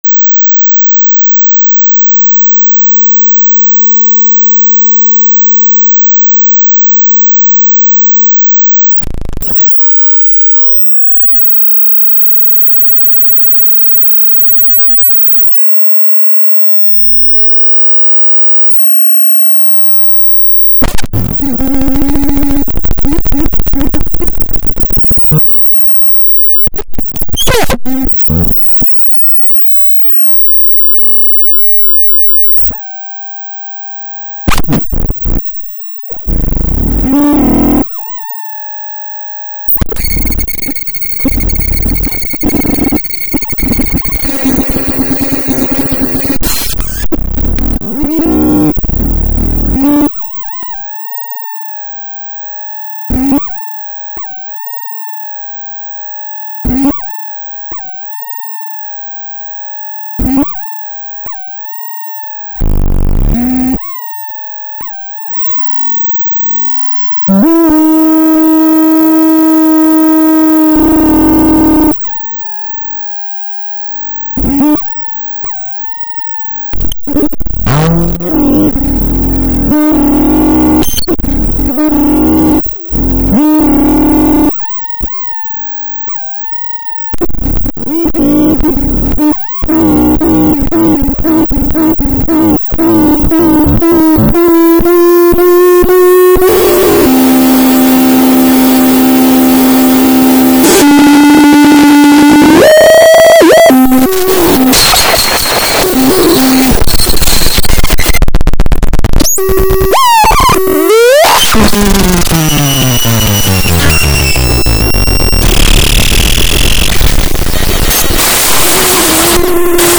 Music composition as a structured organisation of sound
>A composition for a fishbowl, mixing board and human body<